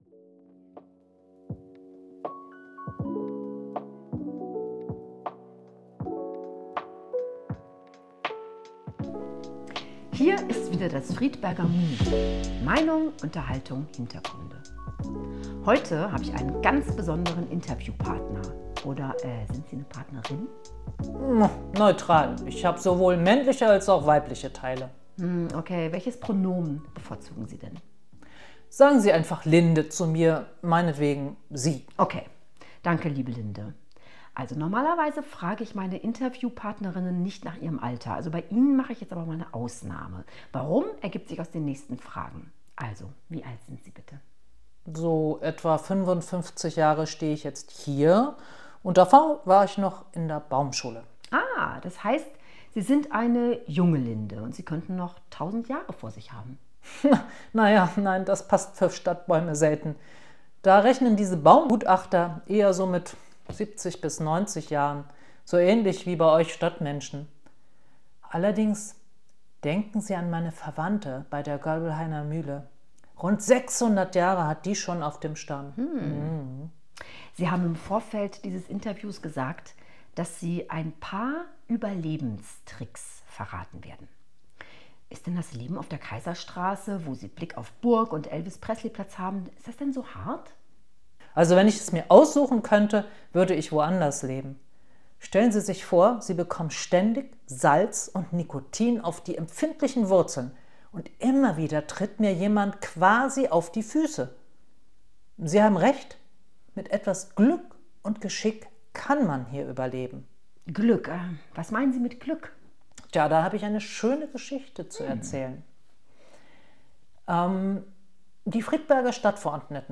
Gespraech_mit_Linde-geschnitten(1).mp3